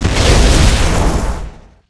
大怪死亡倒地zth070518.wav
通用动作/01人物/02普通动作类/大怪死亡倒地zth070518.wav
• 声道 立體聲 (2ch)